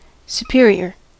superior: Wikimedia Commons US English Pronunciations
En-us-superior.WAV